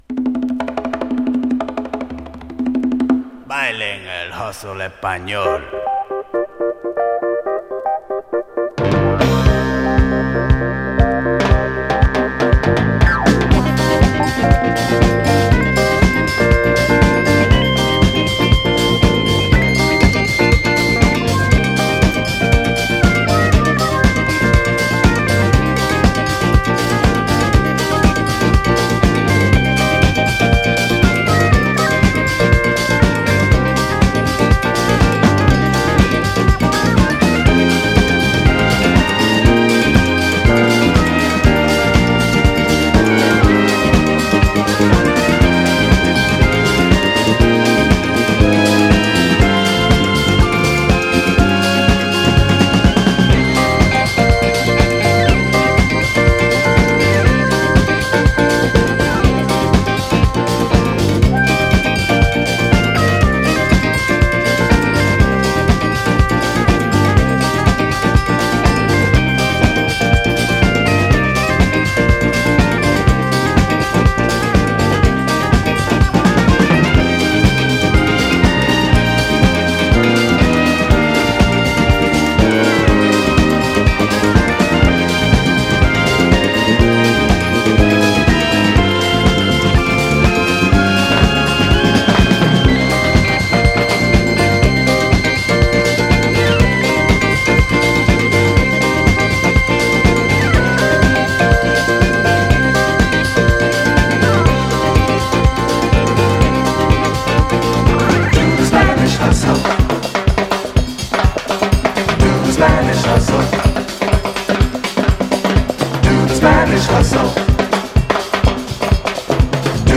DISCO
PARADISE GARAGE〜RARE GROOVE CLASS…